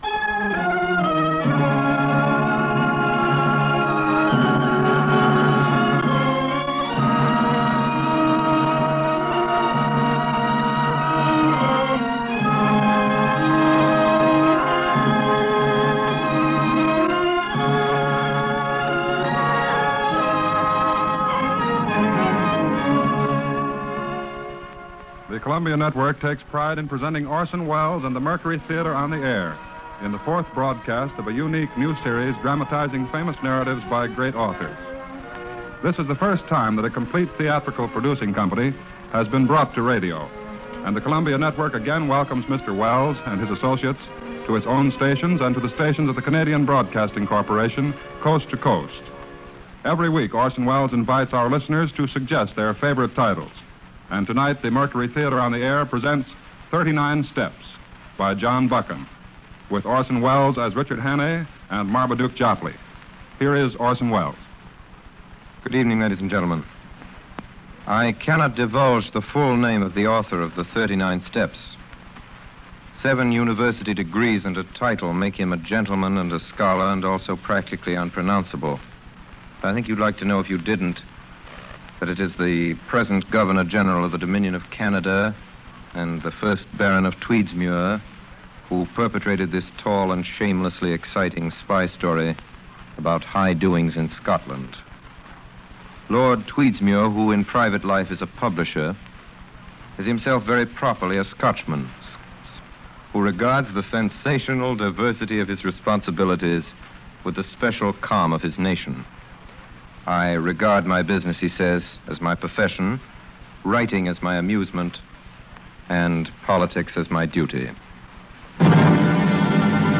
The 39 Steps (rehearsal)